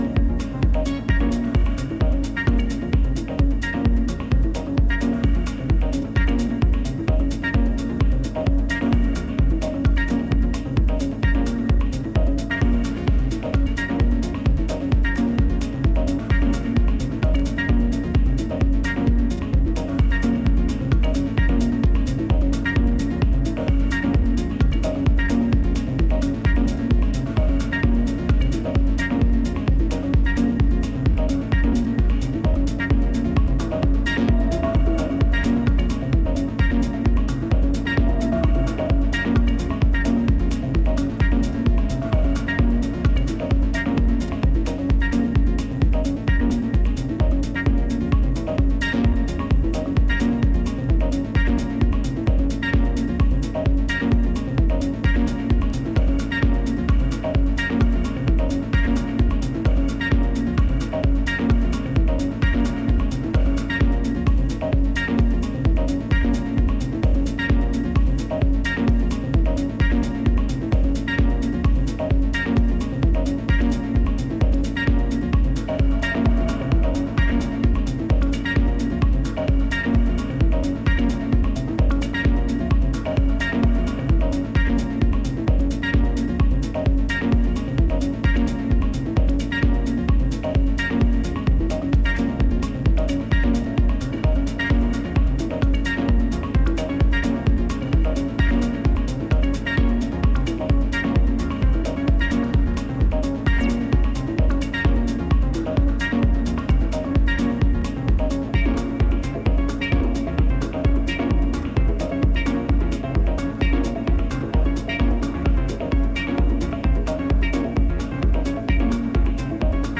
rolling trippy minimlized techno tracks
Techno New York